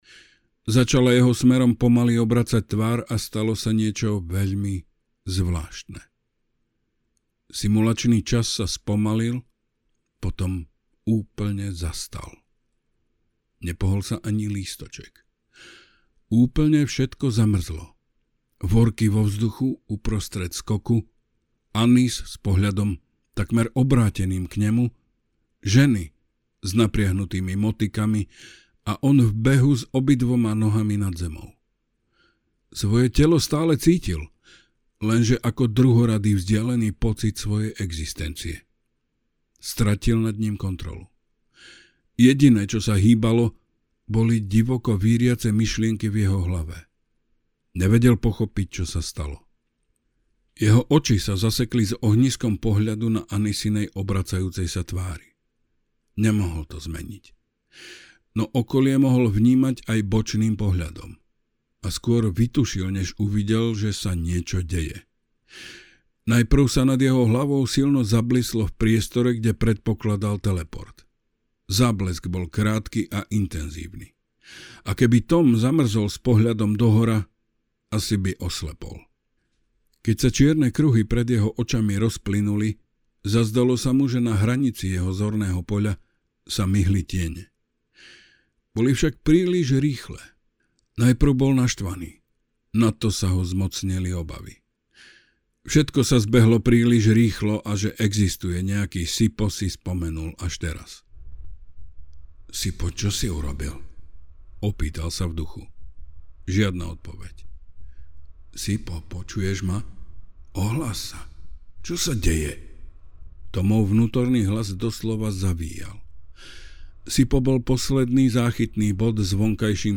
Zabudnutý vesmír audiokniha
Ukázka z knihy